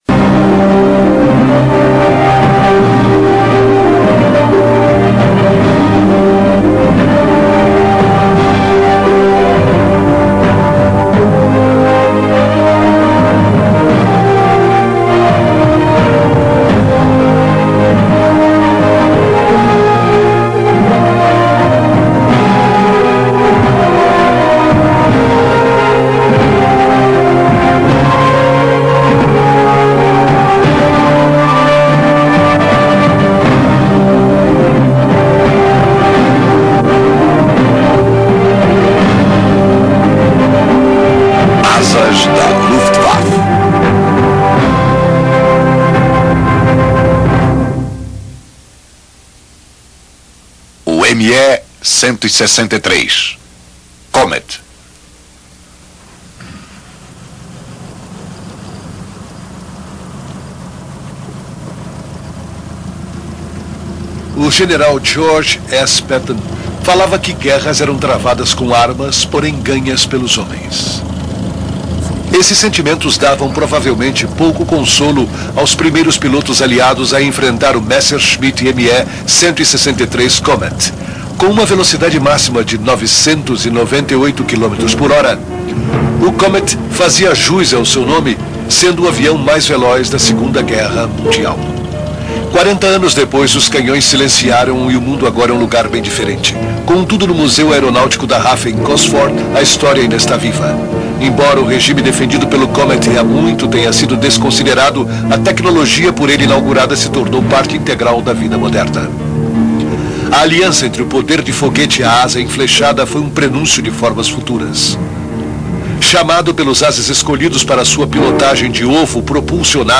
Este documentário é para entusiastas da aviação mundial, mostrando um pouco da grande indústria aeronáutica alemã da segunda guerra mundial.